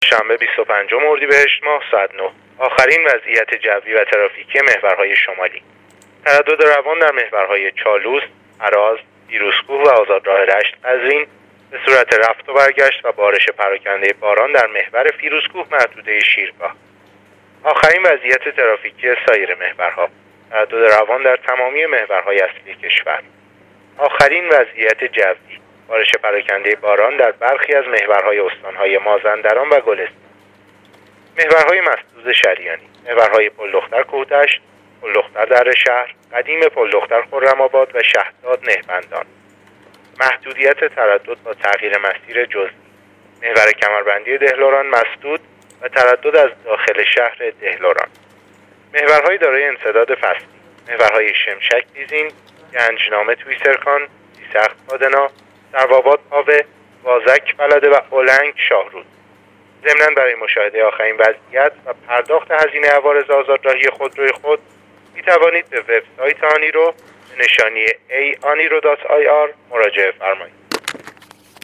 گزارش رادیو اینترنتی وزارت راه و شهرسازی از آخرین وضعیت‌ ترافیکی راه‌های کشور تا ساعت ۹ بیست و پنج اردیبهشت/تردد روان در تمامی محورهای اصلی کشور/ بارش پراکنده باران در برخی از محورهای استان‌های مازندران و گلستان